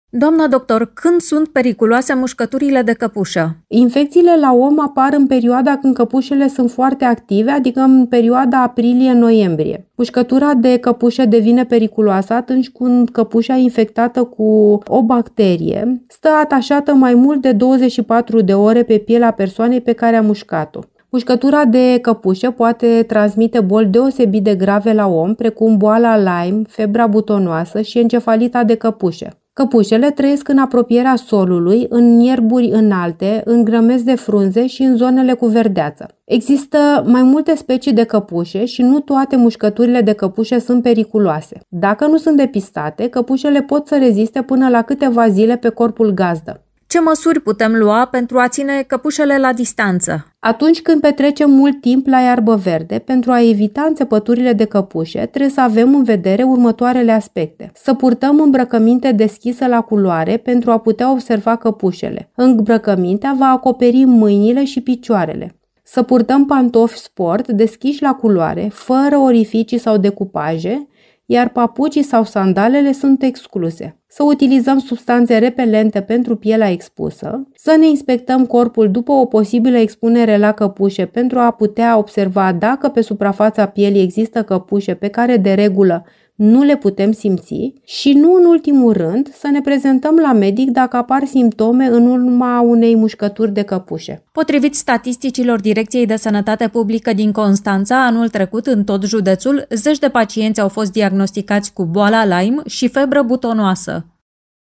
în dialog